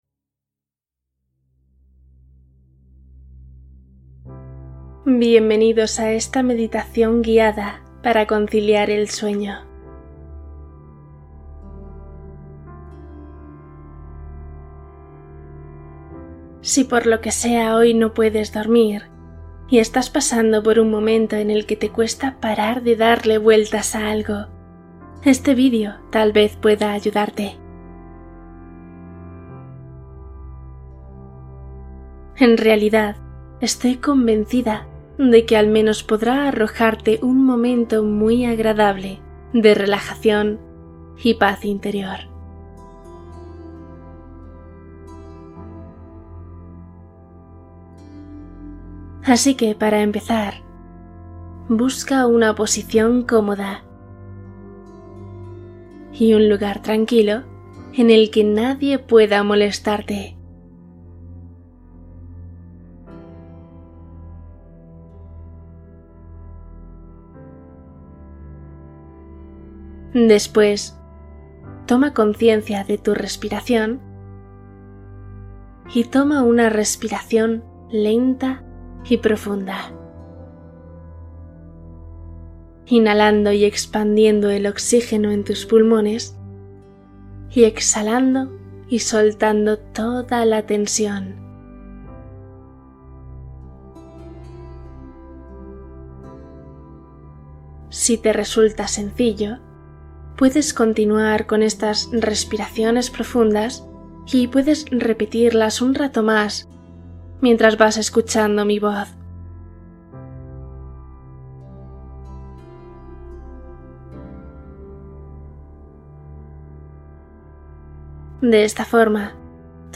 Relajación guiada | Dormir y despertar con felicidad